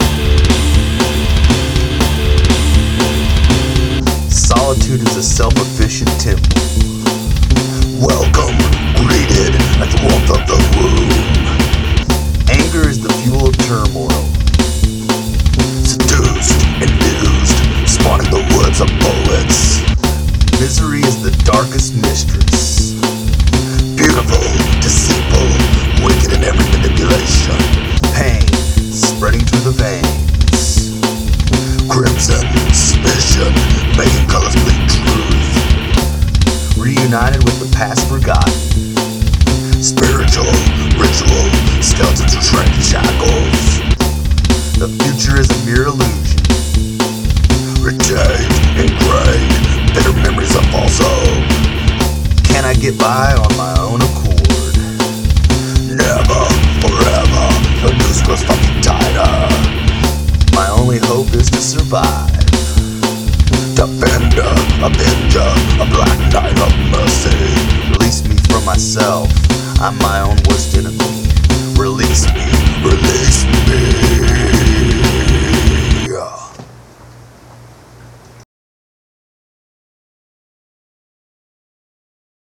Poetry
I’ve always enjoyed the voices that have gravel in their DNA.